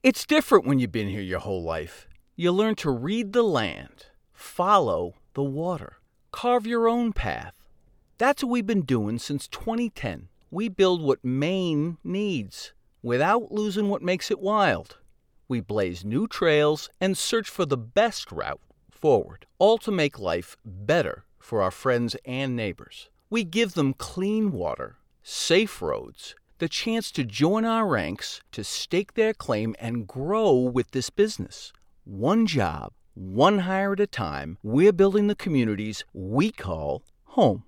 Adult (30-50) | Older Sound (50+)
0331new_england_brand_construction_.mp3